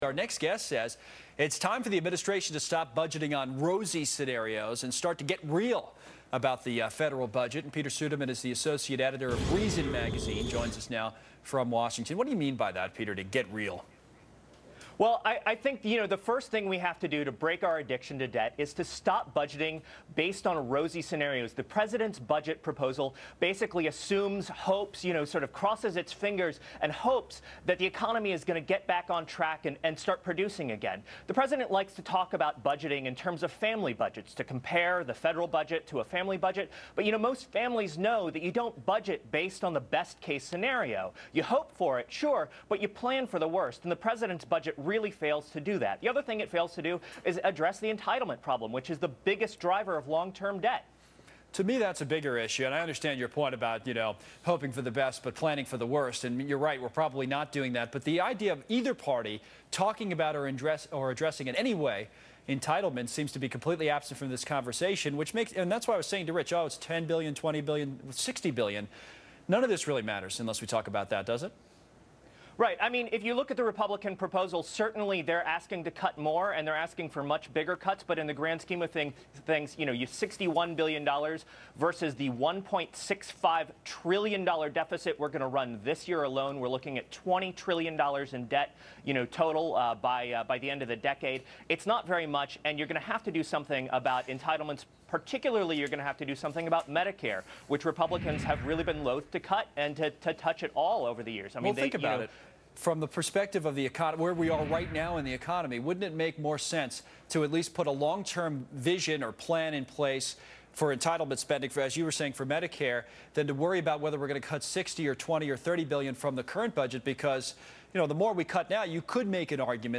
appeared on Fox Business to discuss the budget battle in Washington DC and why every part of the budget, including entitlement spending, must be part of the conversation.